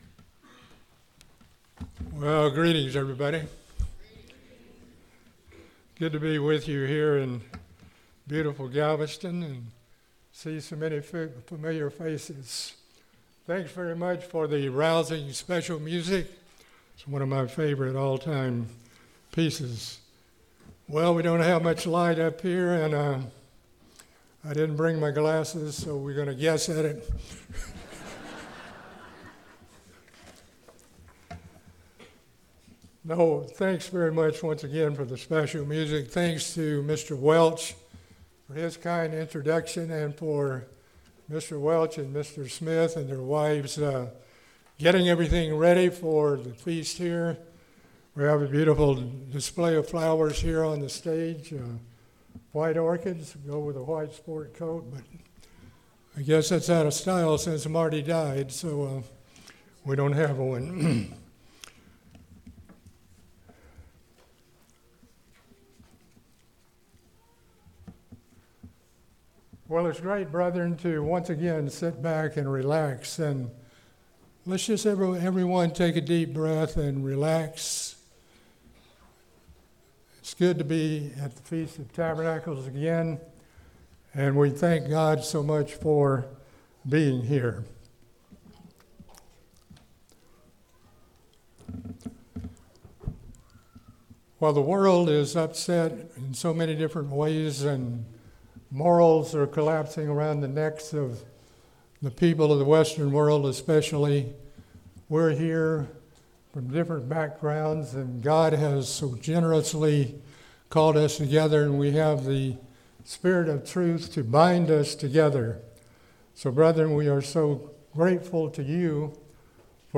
This sermon was given at the Galveston, Texas 2023 Feast site.